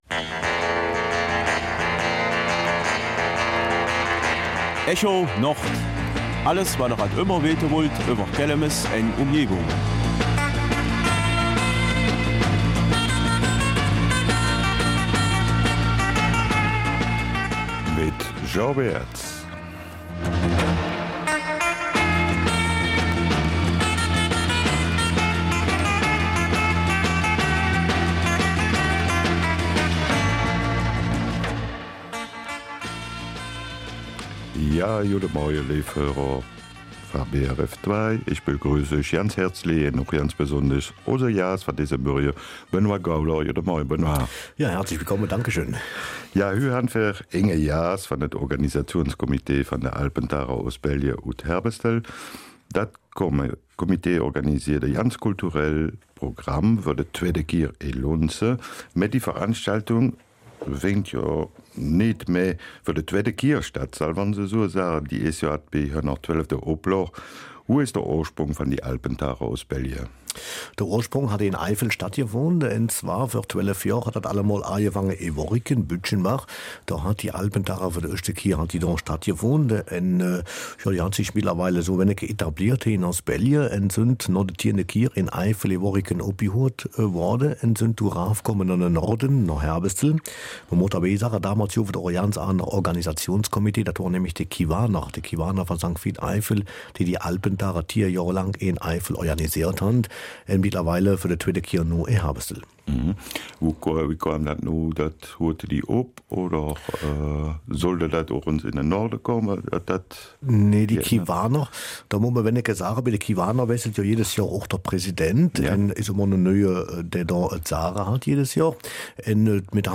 Kelmiser Mundart: Alpentage Ostbelgien 06.